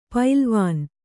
♪ pailvān